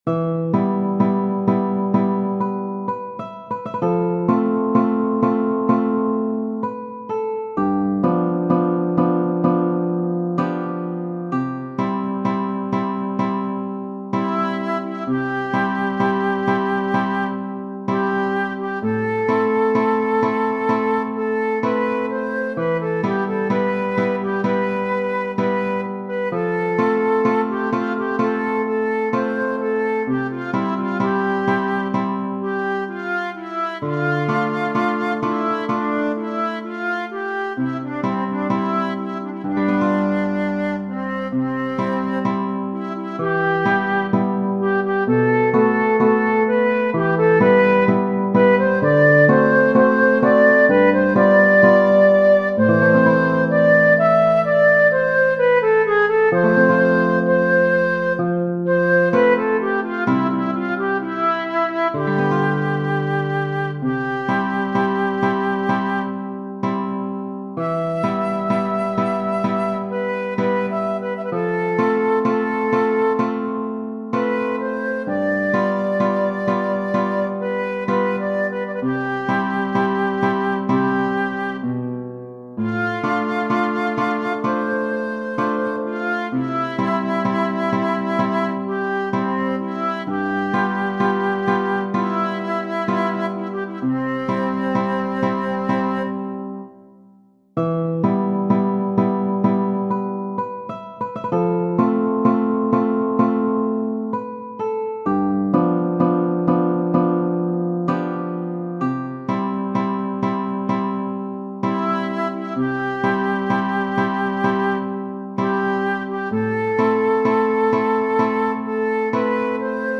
Cardillo, S. Genere: Napoletane Testo di A. Sisca Catarì, Catarì, pecché me dice sti parole amare, Pecché me parle e 'o core me turmiente Catarì?